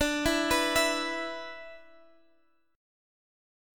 E5/D chord